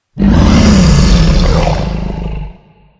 Sound / Minecraft / mob / enderdragon / growl3.ogg
growl3.ogg